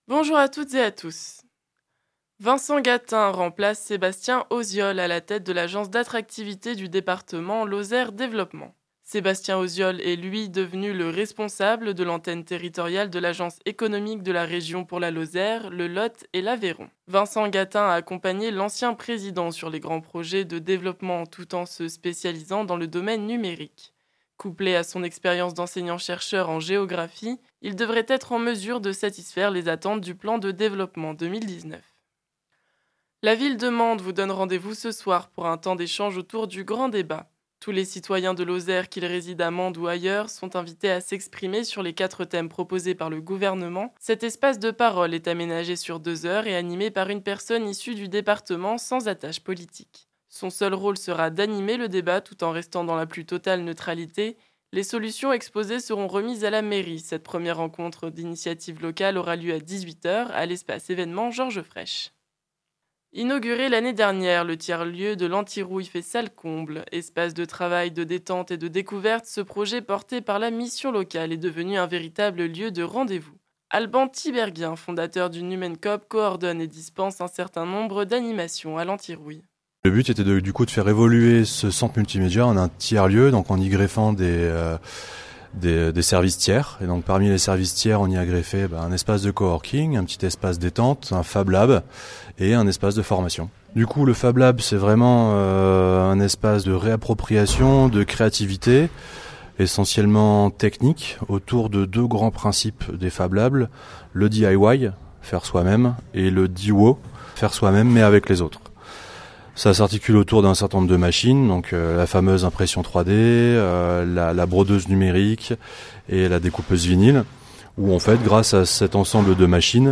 Les informations locales